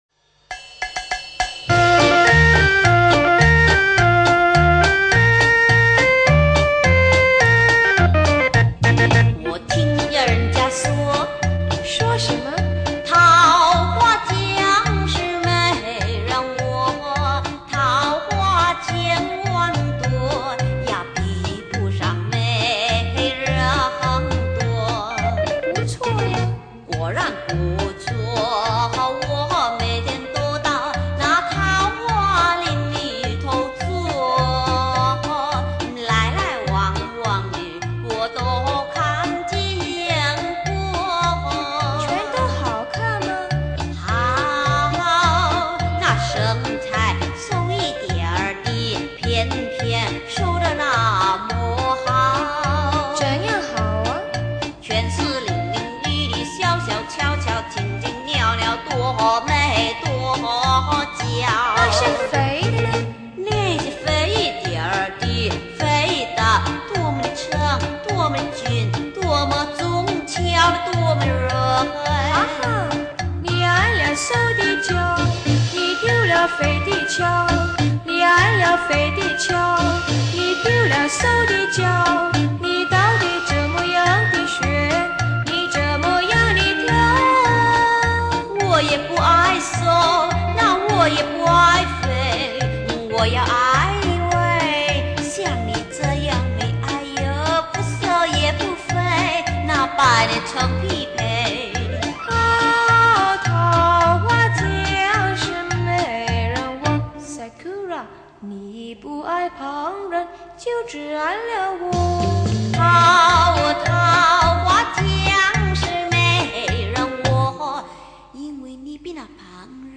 此版本虽不是正宗原唱，但听起来更有味道更可爱，不信你听试试